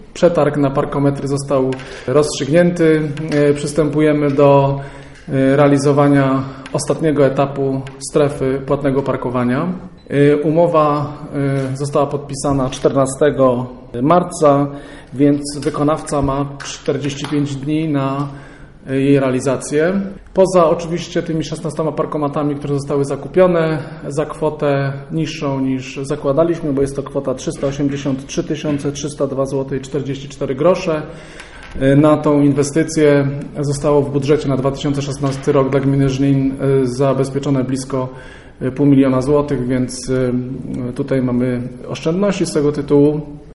Dziś w auli Urzędu Miejskiego w Żninie odbyła się sesja rady miasta.
Podczas obrad żnińscy rajcy zastanawiali się nad pojęciem uchwały zmieniającej uchwałę w sprawie ustalenia stawek opłat za gospodarowanie odpadami komunalnymi, która przypomnijmy przyjęta została 28 października, a obowiązuje od stycznia tego roku, mówi burmistrz Robert Luchowski.